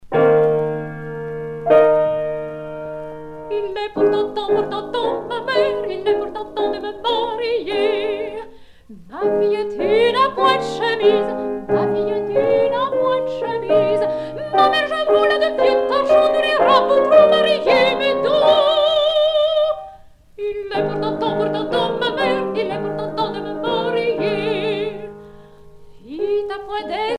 Genre dialogue
Pièce musicale éditée